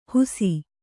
♪ husi